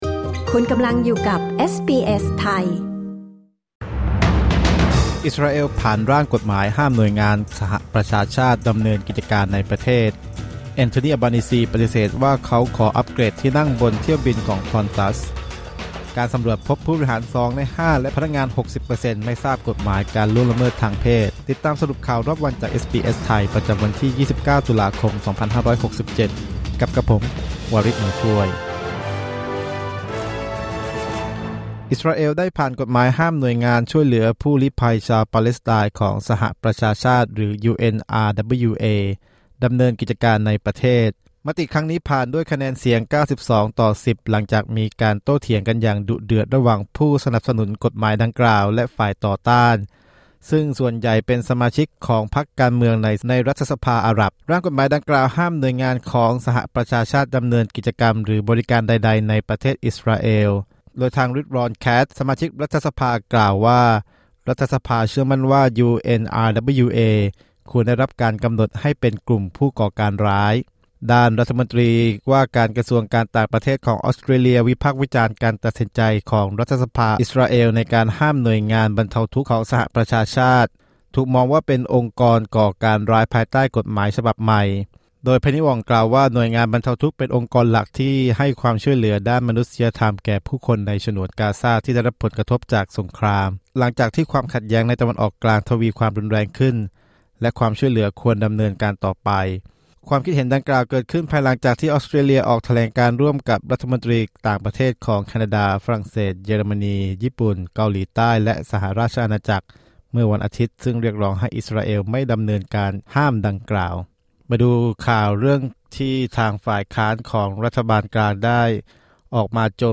สรุปข่าวรอบวัน 29 ตุลาคม 2567